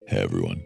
hey-everyone-deep.mp3